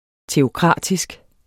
Udtale [ teoˈkʁɑˀdisg ]